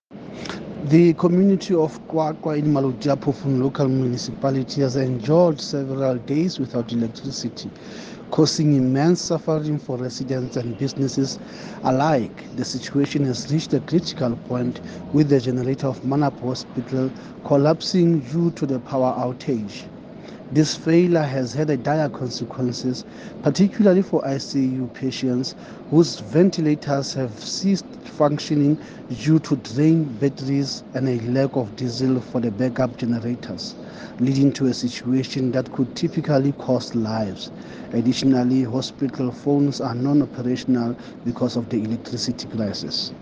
Sesotho soundbites by Cllr Moshe Lefuma.